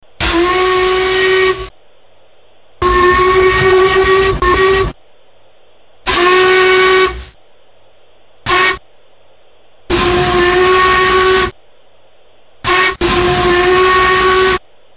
Recorded Whistles for Live Steam Locomotives
whistles_blanche.mp3